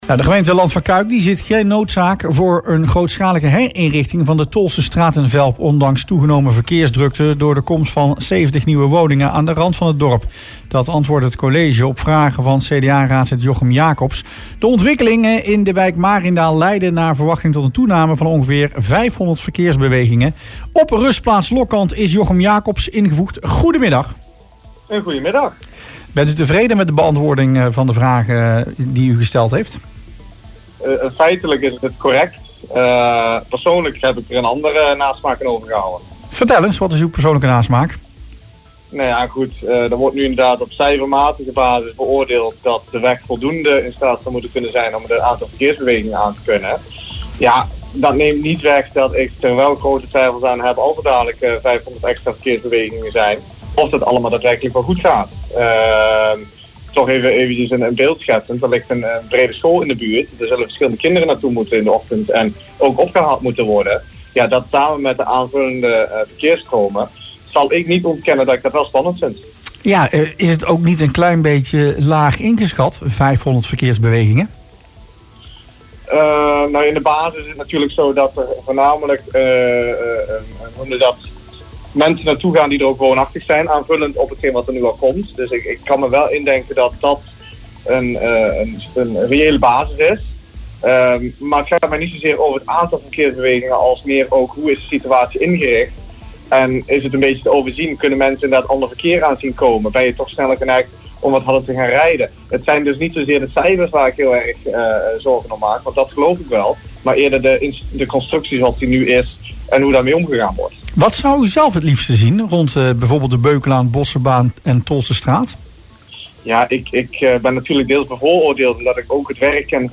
VELP - De beantwoording van vragen over verkeersveiligheid op de Tolschestraat in Velp is volgens CDA-raadslid Jochem Jacobs “feitelijk correct”, maar zorgt desondanks bij hem voor een vervelende nasmaak. Hij zei dat in het radioprogramma Rustplaats Lokkant.
Jacobs uit in het radioprogramma zijn zorgen: